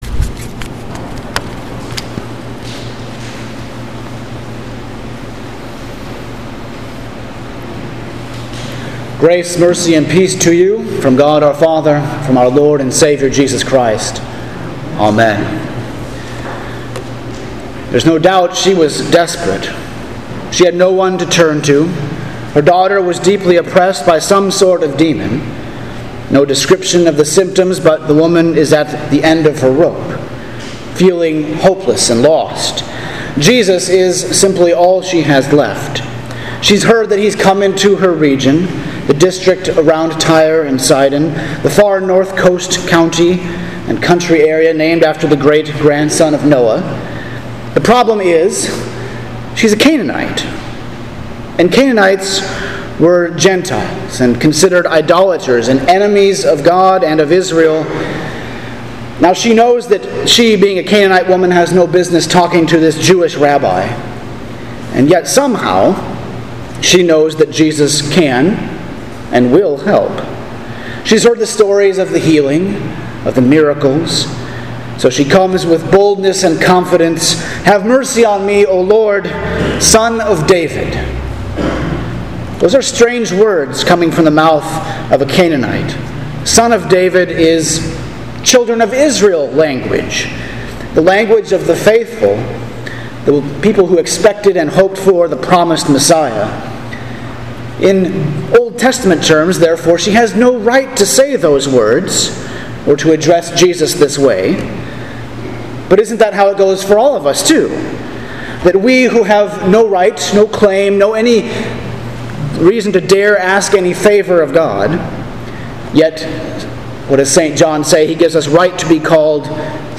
Sermon for Pentecost 11 – August 16th, 2020